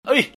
布鲁叫声.mp3